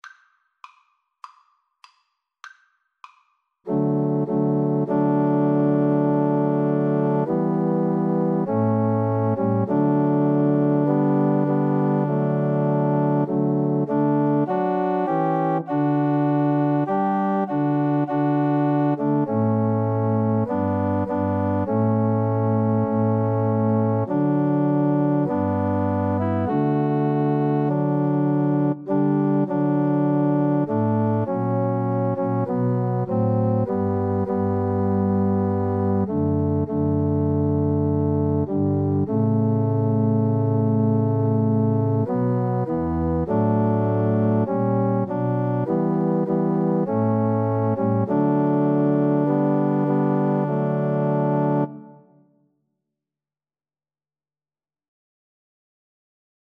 4/4 (View more 4/4 Music)